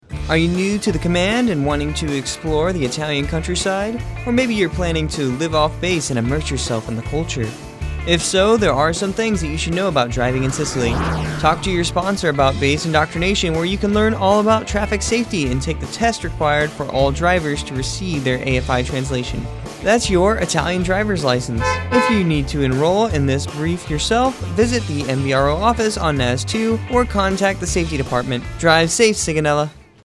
NAVAL AIR STATION SIGONELLA, Italy (Apr. 13, 2026) Radio spot promotes required classes for driving in Sicily.